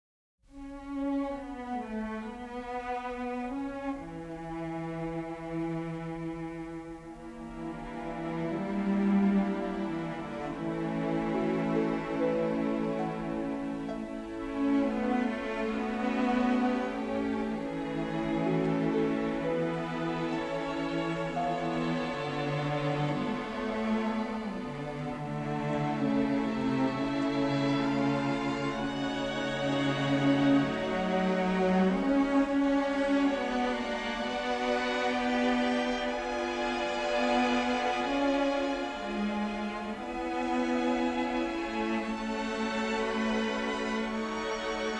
The complete symphonic score is presented in stereo